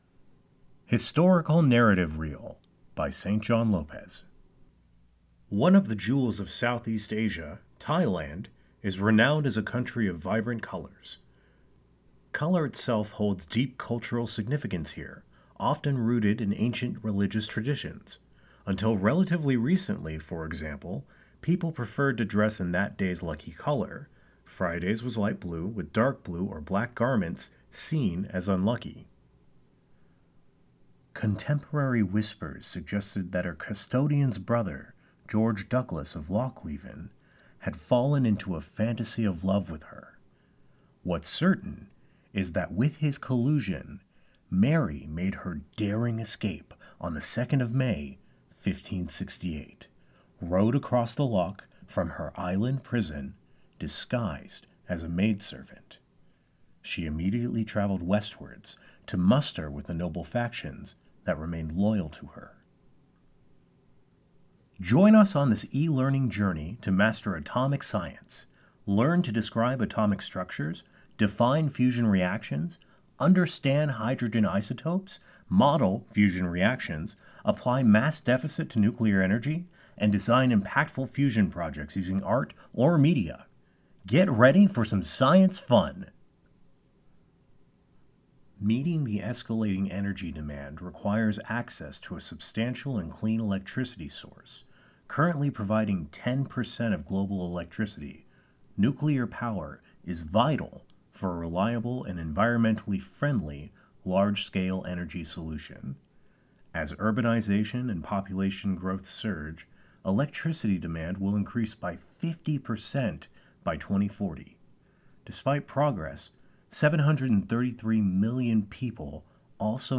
Historic/Narrative Reel (2024) - Informative, Measured, Knowledgable, Instructive Commentary.
Historic Narrative Reel.mp3